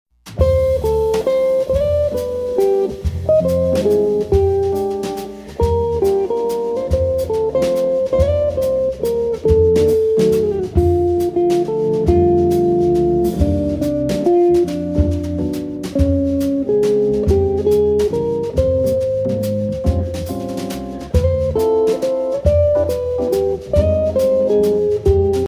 chitarra
batteria
hammond
inciso in California
è eseguito in trio